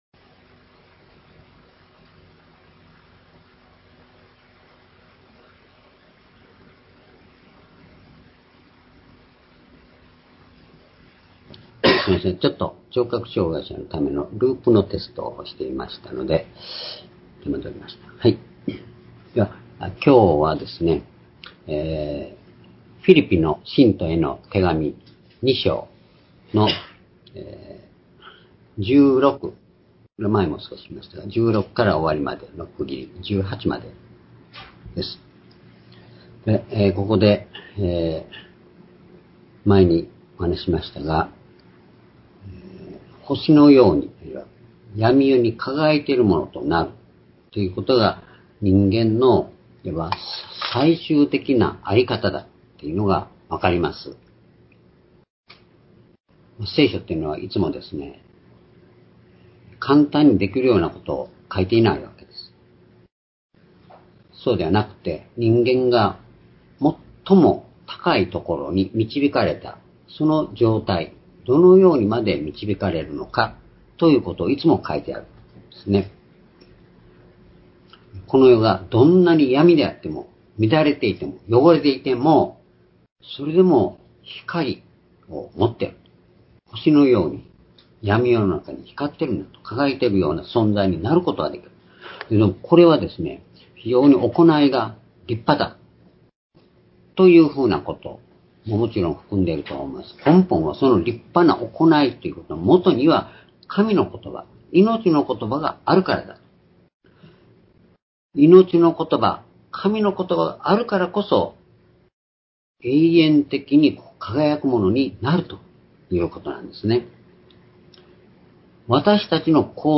「主にあって喜ぶ」-フィリピ書2章16節～18節-2020年9月13日（主日礼拝）